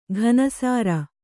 ♪ ghana sāra